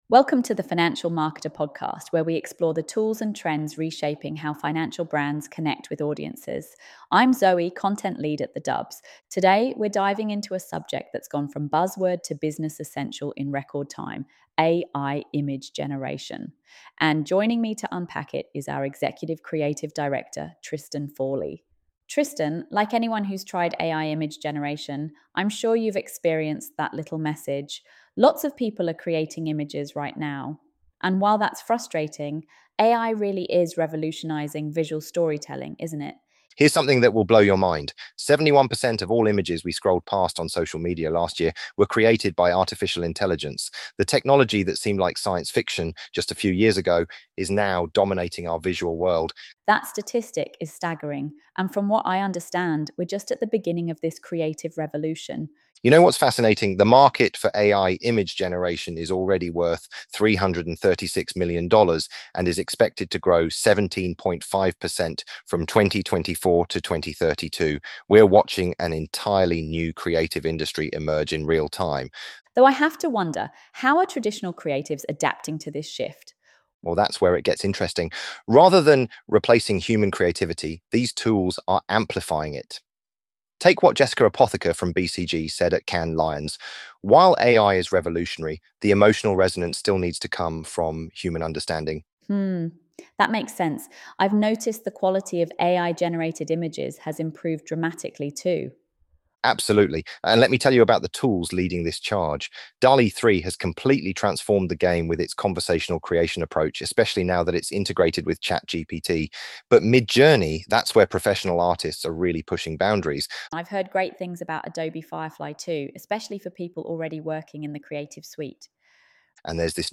The AI Art Revolution: Creativity Unleashed (AI podcast)
ElevenLabs_The_AI_Art_Revolution_Creativity_Unleashed-1.mp3